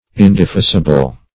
\In`de*fei"si*ble\